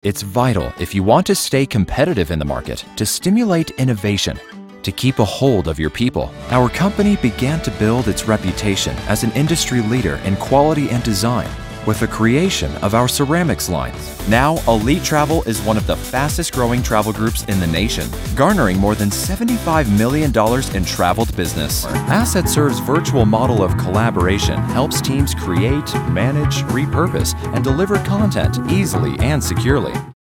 Conversational, Real, Edgy, Versatile, Professional, Cool, Hip, Compelling, Funny, Narrator
Sprechprobe: Industrie (Muttersprache):